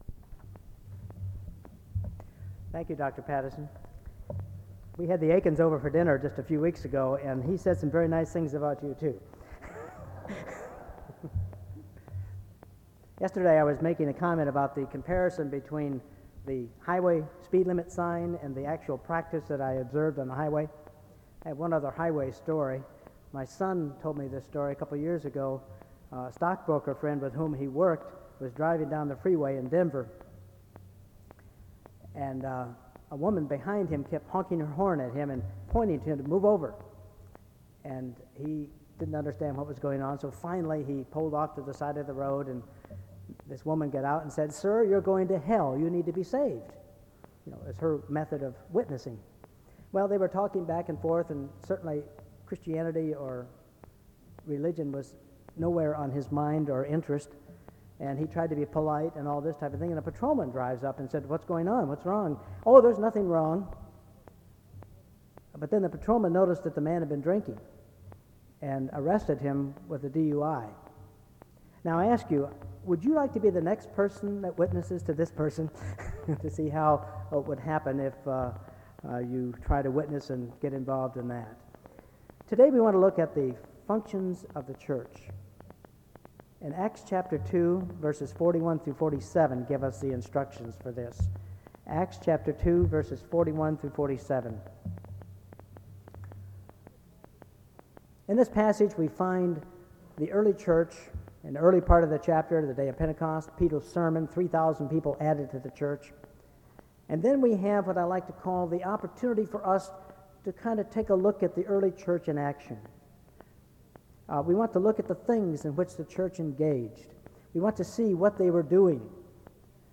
SEBTS Kendrick-Poerschke Lecture
SEBTS Chapel and Special Event Recordings